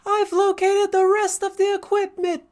DRG-Femboy-Voice